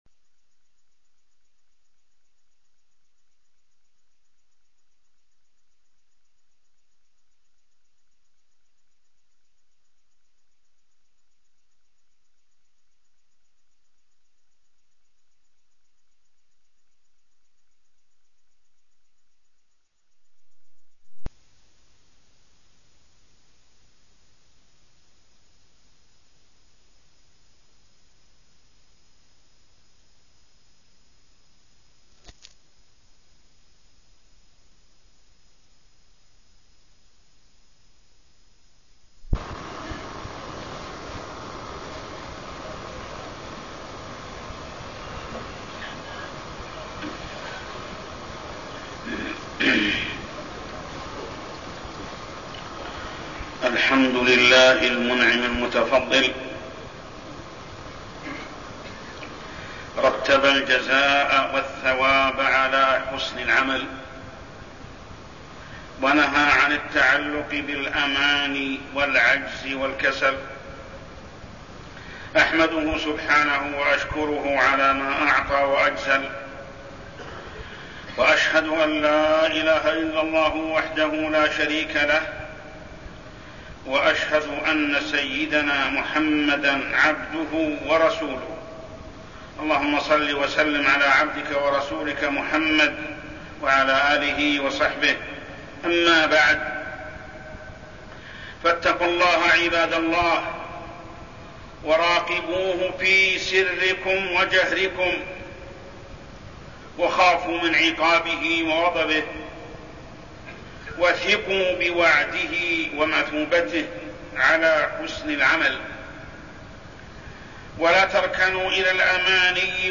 تاريخ النشر ١٥ جمادى الآخرة ١٤١٥ هـ المكان: المسجد الحرام الشيخ: محمد بن عبد الله السبيل محمد بن عبد الله السبيل المصاح الروحية المادية